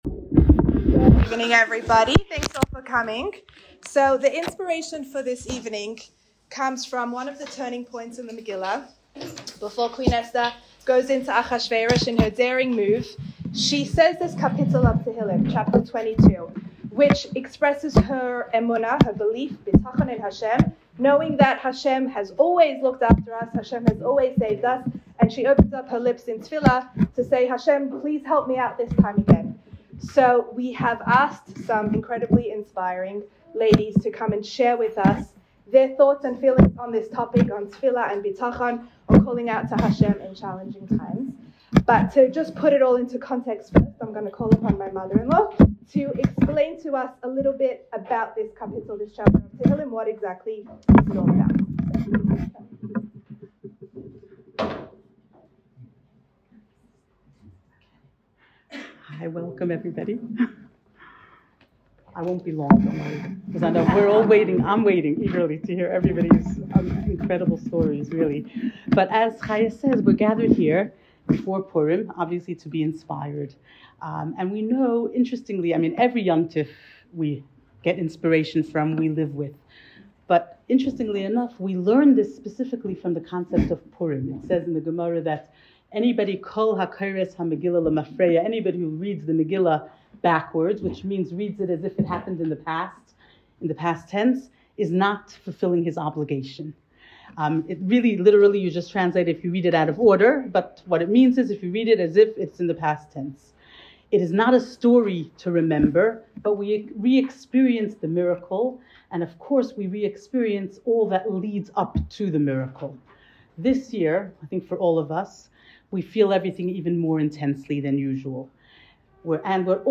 This event was attended by over 100 women and was truly an amazing evening.